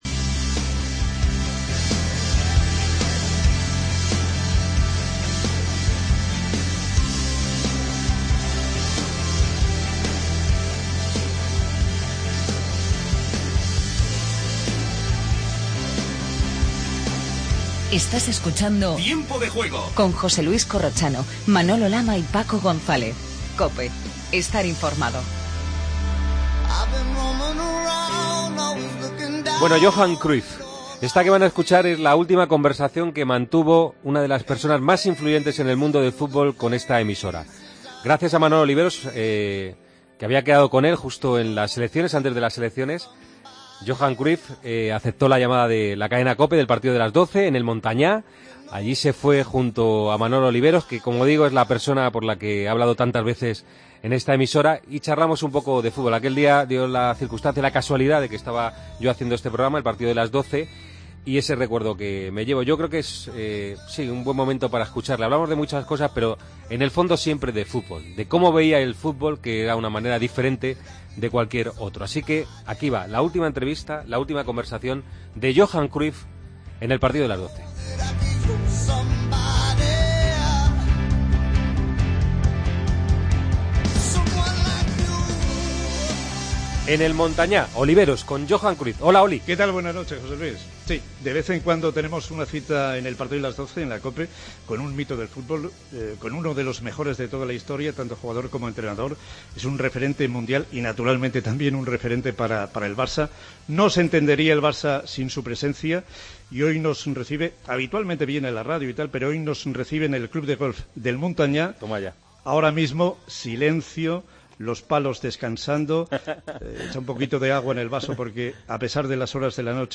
AUDIO: Escuchamos la última entrevista de Johan Cruyff en El Partido de las 12. Agenda del día y portada MARCA.